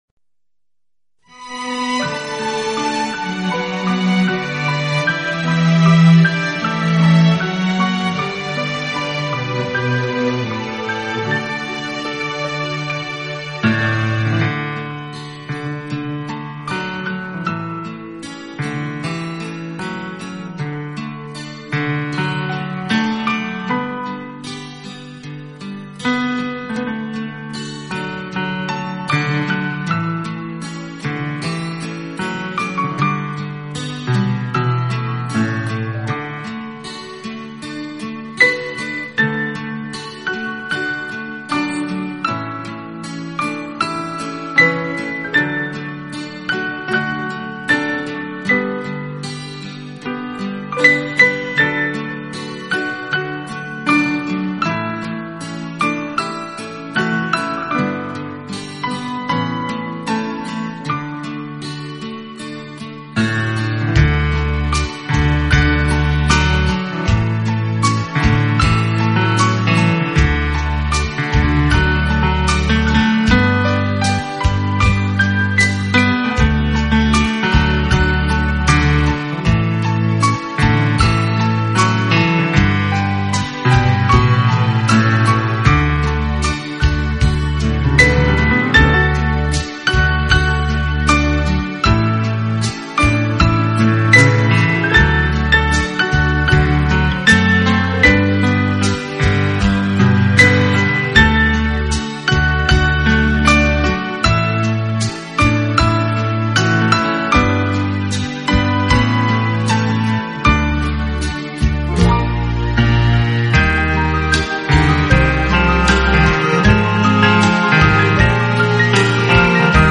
【浪漫钢琴】
钢琴演奏版，更能烘托出复古情怀，欧美钢琴大师深具质感的演奏功力，弹指
本套CD全部钢琴演奏，